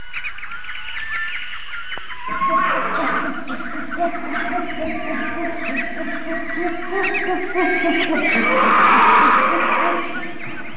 دانلود صدای حیوانات جنگلی 1 از ساعد نیوز با لینک مستقیم و کیفیت بالا
جلوه های صوتی